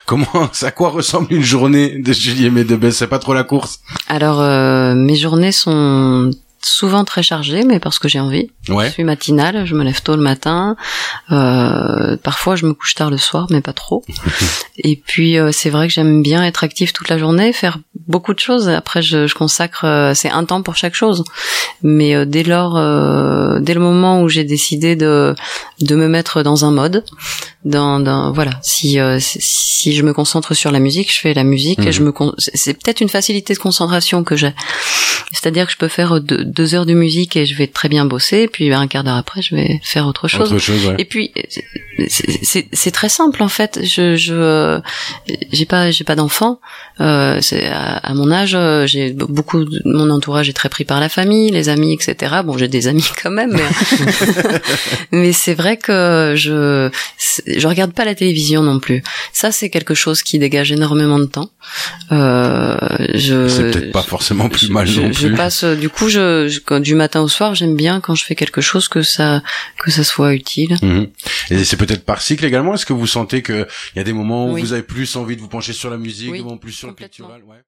Extrait Voix
Interview